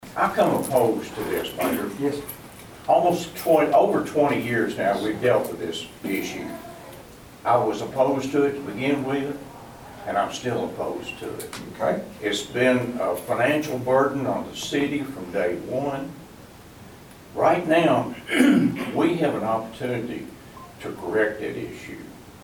At a town hall Wednesday night, the room may have been full of residents and city officials, but only a handful voiced opinions on Bluegrass Splash, and those were split.